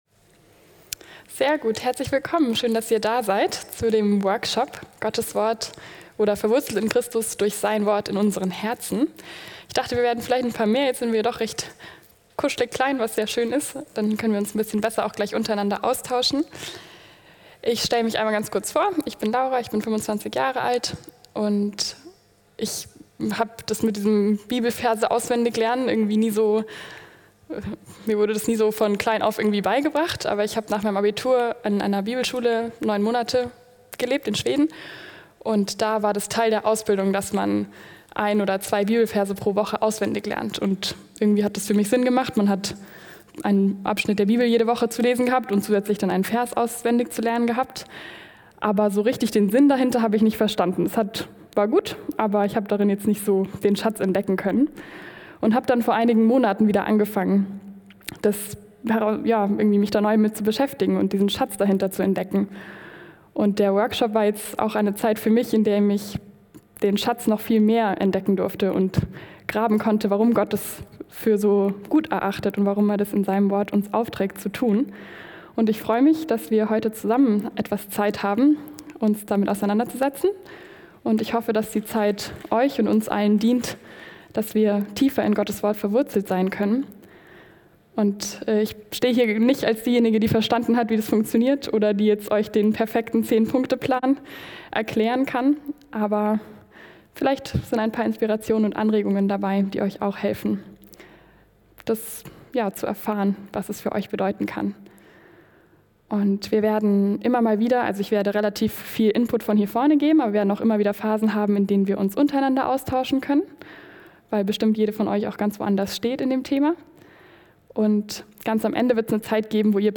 Frauenseminartage 2024 - Workshop: Verwurzelt in Christus durch Sein Wort in unseren Herzen